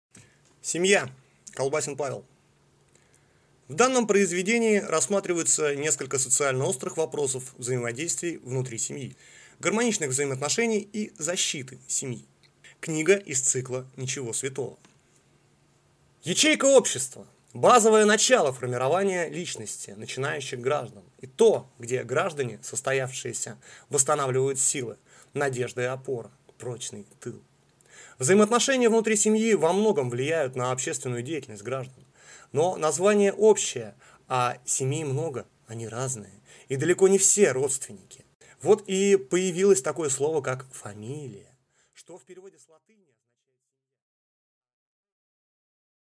Аудиокнига Семья | Библиотека аудиокниг